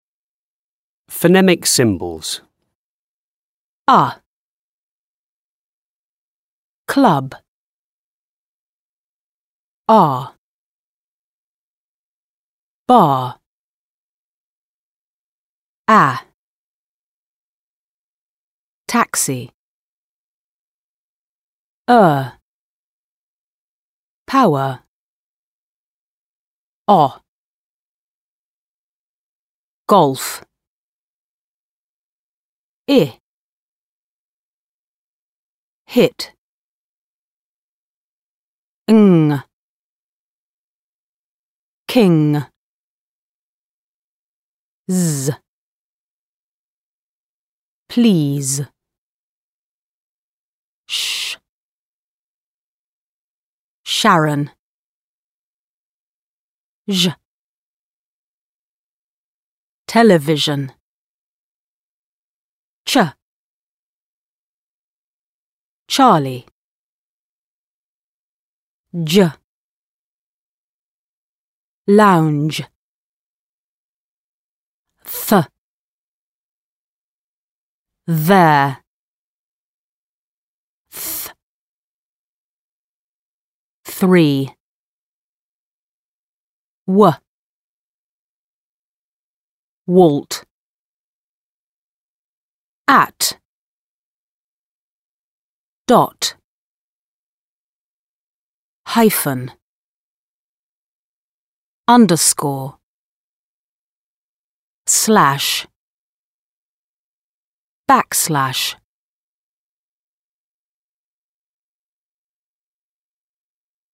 phonemic symbols.mp3